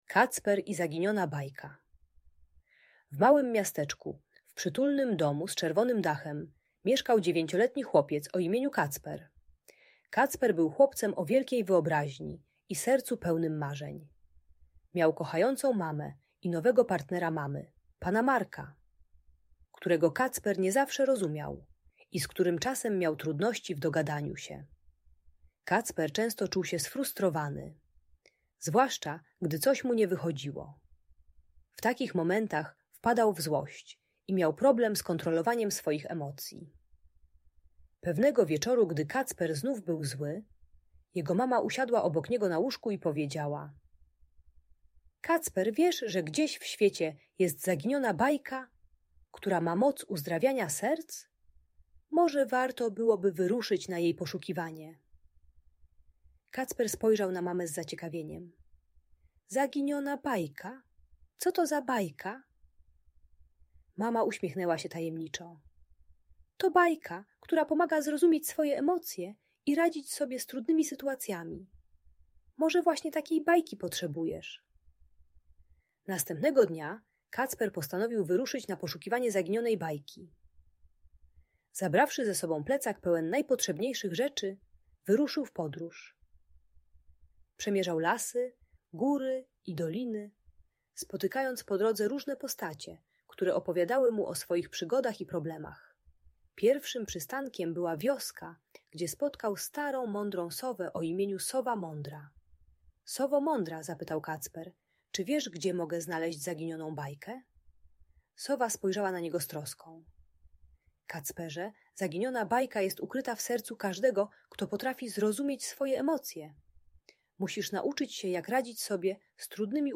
Kacper i Zaginiona Bajka - magiczna historia o emocjach - Audiobajka